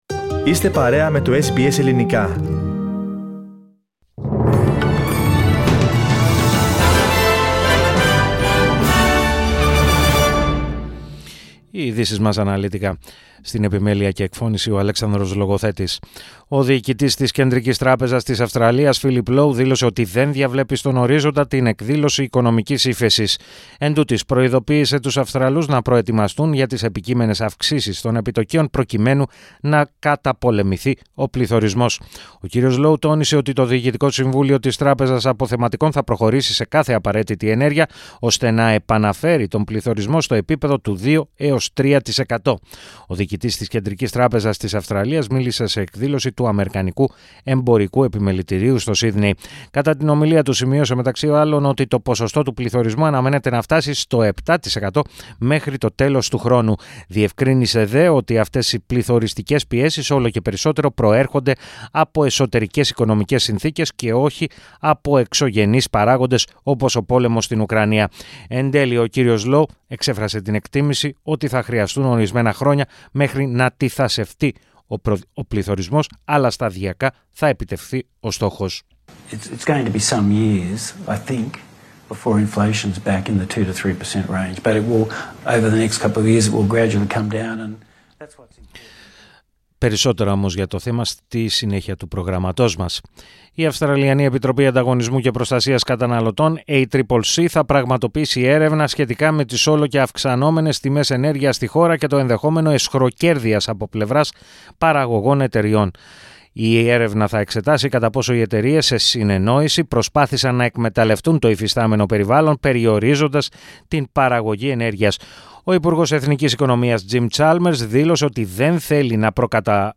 Το αναλυτικό δελτίο ειδήσεων του Ελληνικού Προγράμματος της ραδιοφωνίας SBS, στις 16:00.